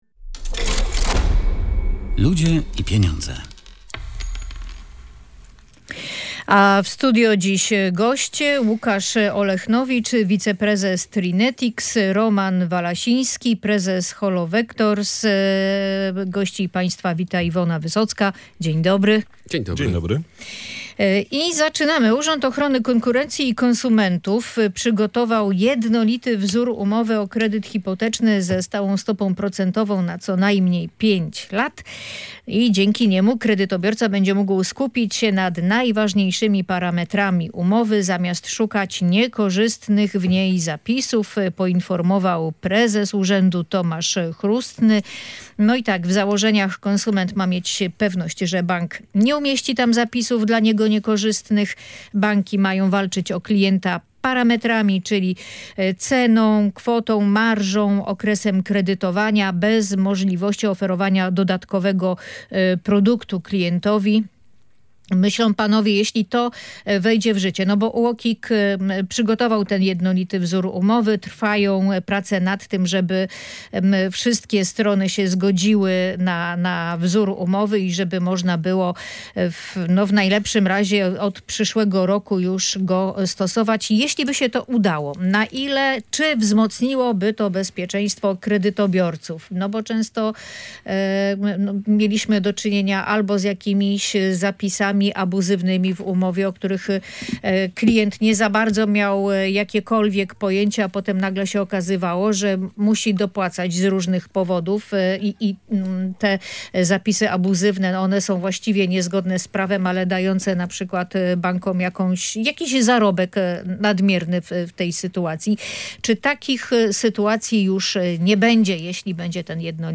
Między innymi na ten temat w audycji „Ludzie i Pieniądze” dyskutowali